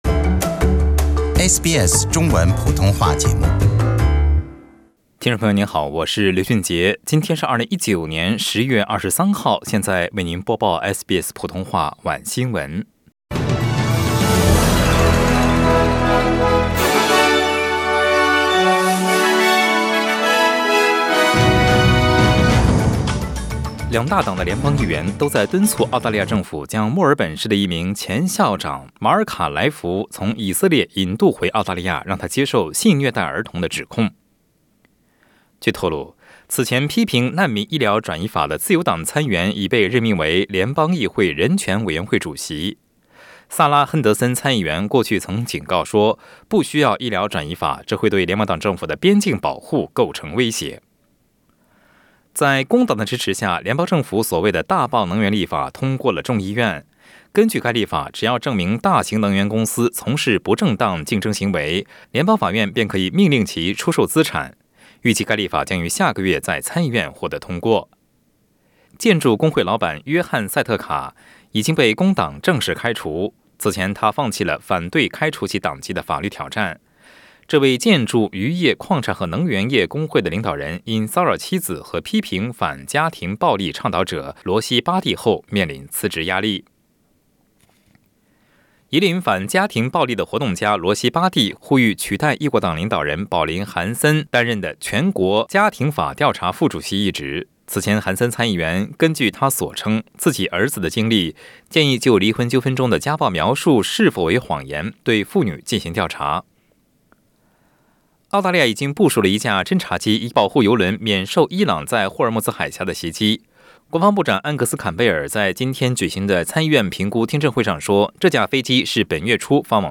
SBS晚新聞（10月23日）